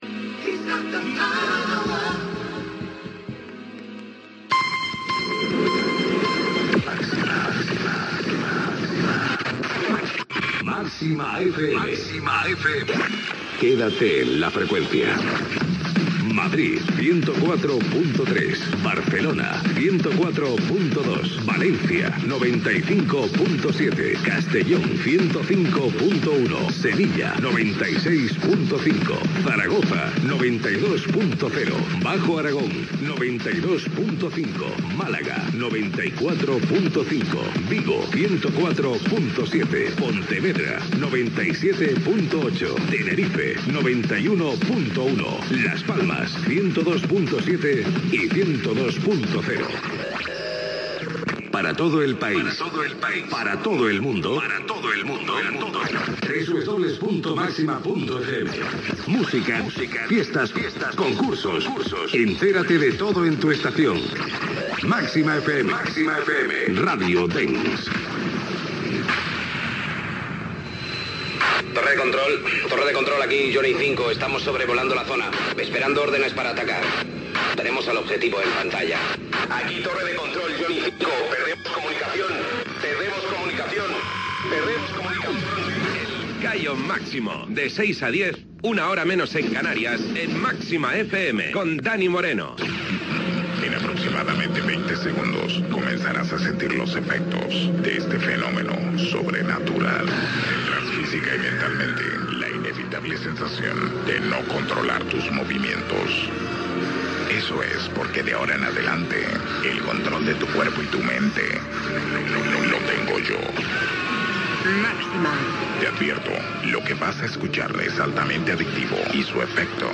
Freqüències de l'emissora i ciutats, indicatiu de la ràdio, careta del programa, data, salutació inicial i temes musicals
Entreteniment